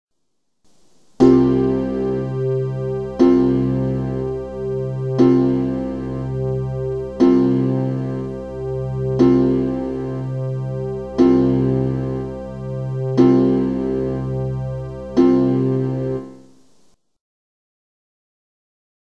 Es erklingt das Intervall aus Höhe und Breite in der gefundenen rhythmischen Struktur der Giebelfront, in der Annahme, dass die Säulenbreite als Klang und die Zwischenräume als kein Klang, also Pause, aufgefasst werden.
Das Element des  Parthenon - Tempel ist aber sicherlich die Säule, die im Ton- Beispiel 1 nur rhythmisch wahrzunehmen ist. Klanglich kommt sie hier nicht vor.